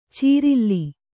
♪ cīrilli